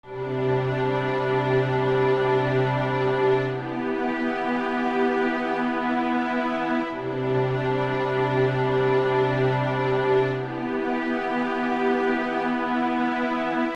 字符串26
描述：在佛罗里达州的工作室制作
Tag: 140 bpm Orchestral Loops Strings Loops 2.31 MB wav Key : Unknown